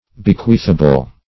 Bequeathable \Be*queath"a*ble\, a. Capable of being bequeathed.
bequeathable.mp3